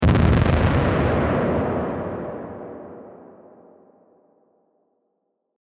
Chunky Explosion
Big thing explode
Chunky Explosion.mp3